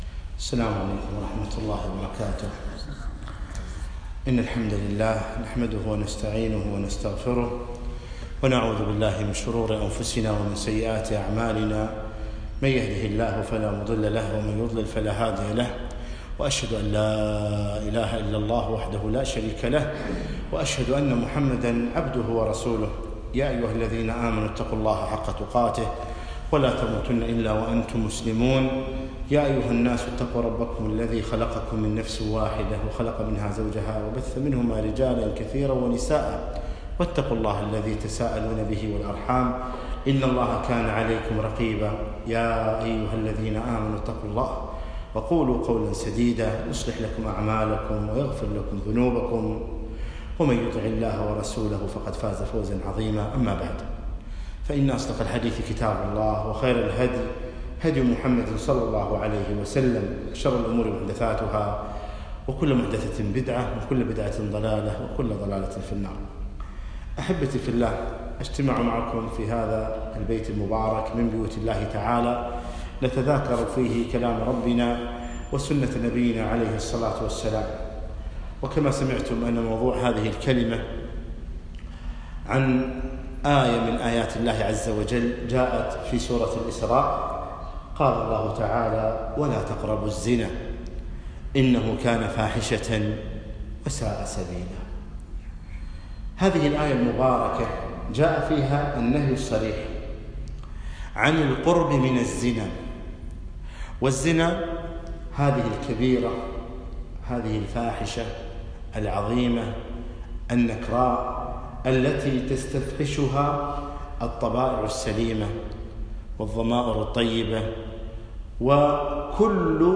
محاضرة - ولاتقربوا الزنا